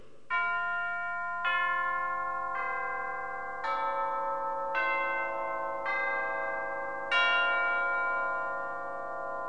campanas.mp3